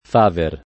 Faver [ f # ver ]